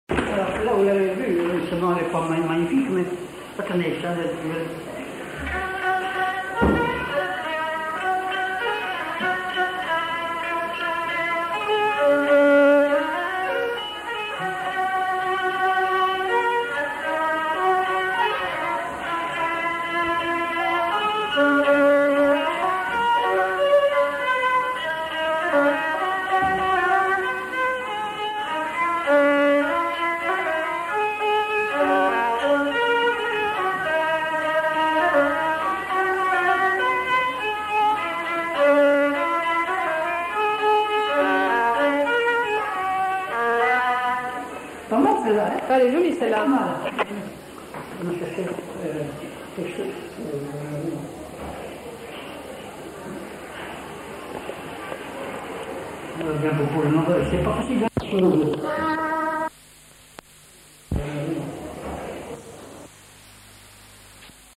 Scottish
Aire culturelle : Haut-Agenais
Lieu : Cancon
Genre : morceau instrumental
Instrument de musique : violon
Danse : scottish